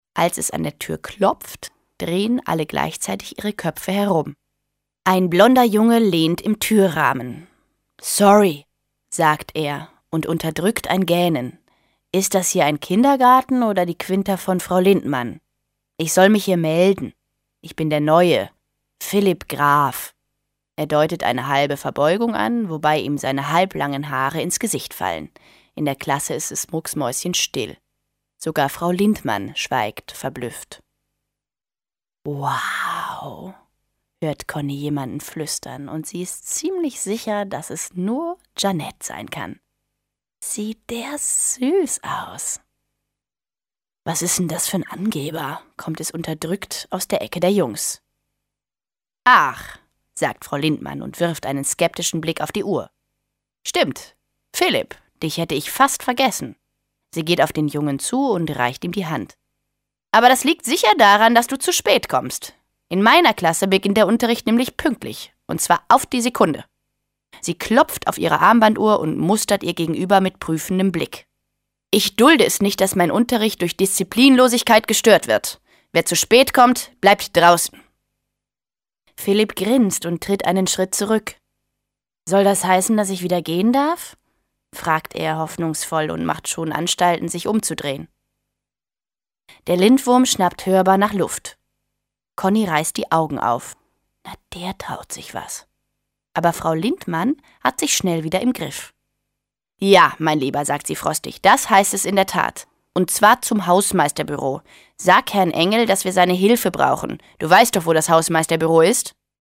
2008 | 6. Auflage, Gekürzte Ausgabe
Kinder- / Jugendbuch Vorlesebücher / Märchen